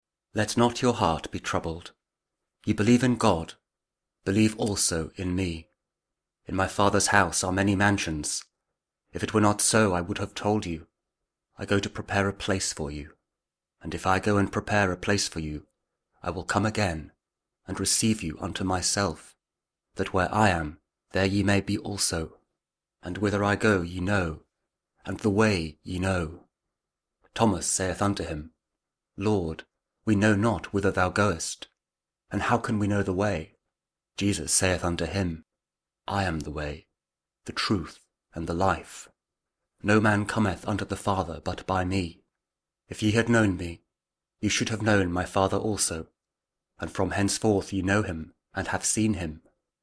The Gospel Of Saint John 14: 1-7 | King James Audio Bible
jesus-king-james-audio-bible-john-14.mp3